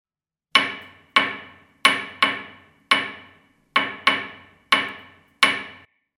Стук соседа в батарею